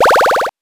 powerup_28.wav